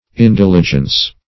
Search Result for " indiligence" : The Collaborative International Dictionary of English v.0.48: Indiligence \In*dil"i*gence\, n. [L. indiligentia: cf. F. indiligence.] Lack of diligence.